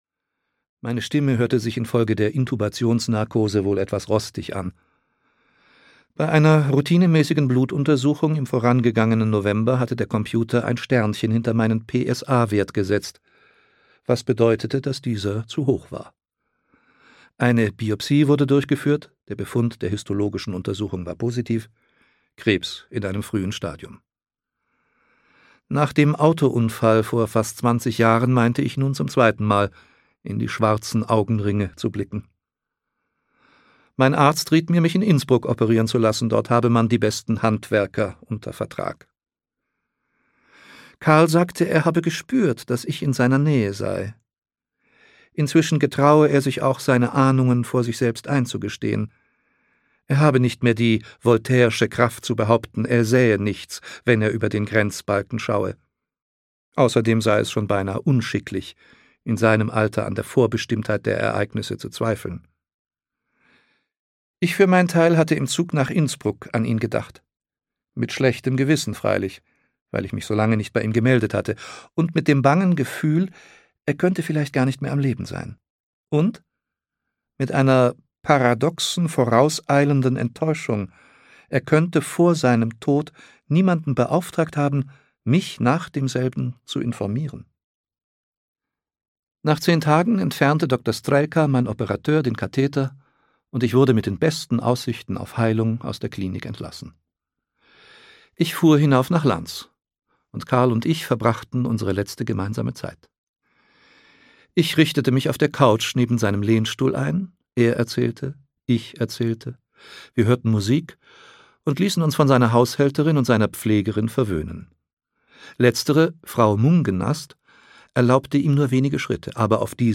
Abendland - Michael Köhlmeier - Hörbuch